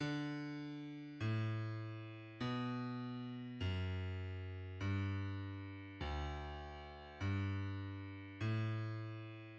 The bassline and harmonic analysis of Pachelbel's Canon